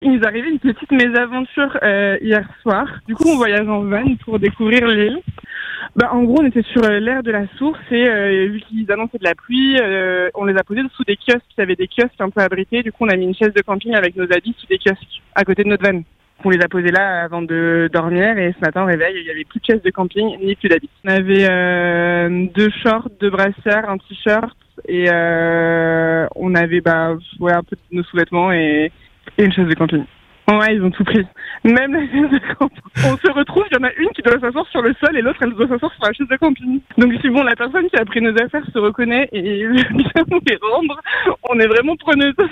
Écoutez leur témoignage dans le podcast